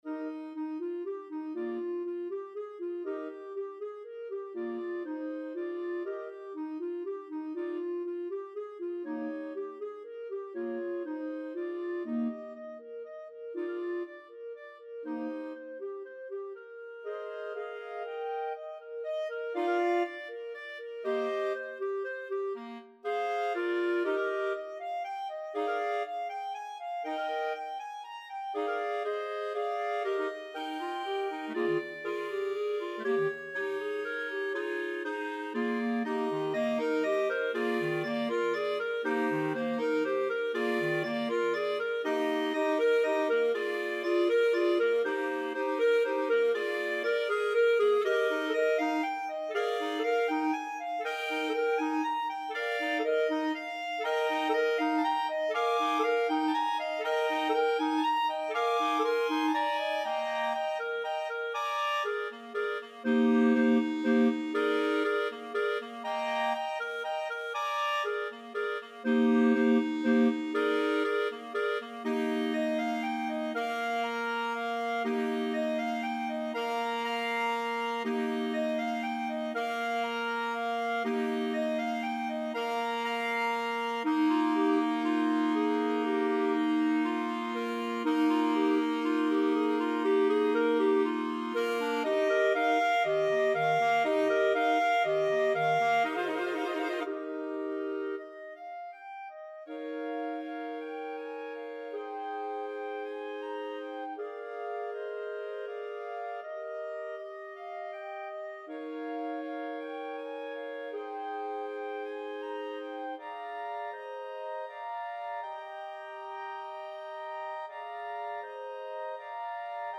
Voicing: Bb Clarinet Quartet